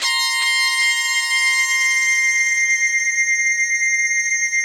45 SYNTH 4-R.wav